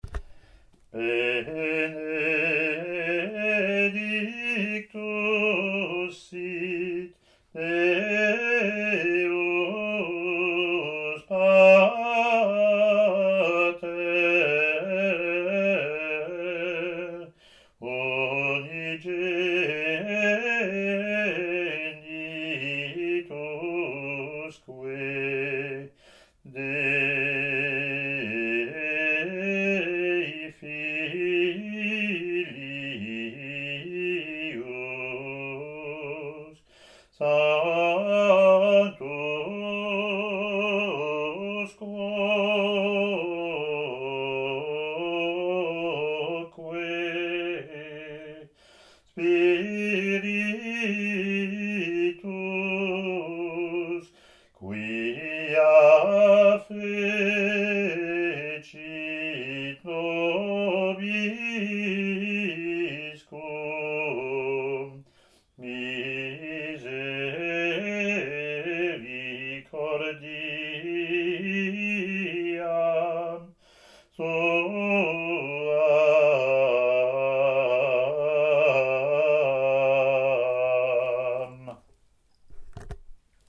Latin antiphon)